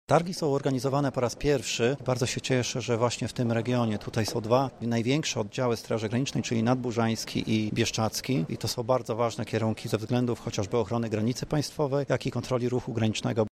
Dlaczego Targi Ochrony Granic zorganizowano w Lublinie mówi generał brygady Straży Granicznej, Tomasz Praga, zastępca komendanta głównego:
Targi „Granice”